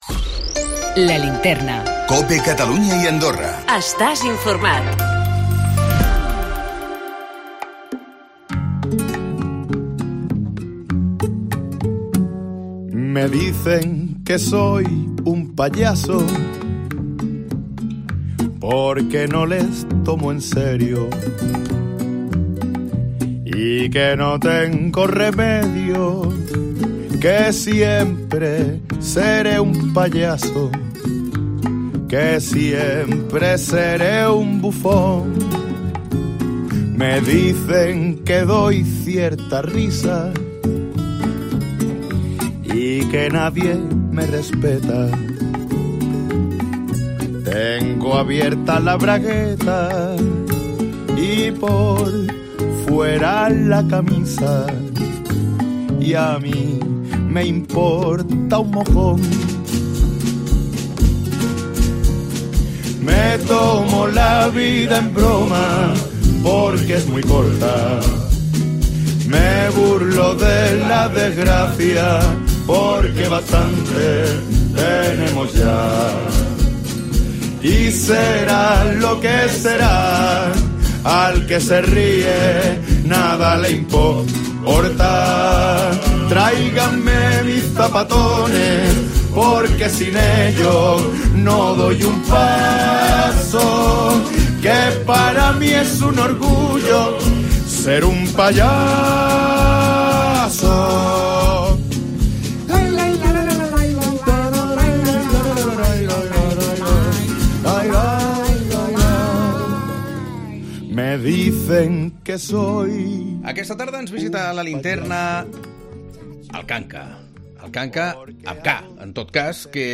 Redacción digital Madrid - Publicado el 03 oct 2019, 18:34 - Actualizado 16 mar 2023, 10:19 1 min lectura Descargar Facebook Twitter Whatsapp Telegram Enviar por email Copiar enlace El cantautor Juan Gómez Canca, més conegut com "El Kanka" ens visita per presentar-nos la seva nova gira "Donde caben 2 caben".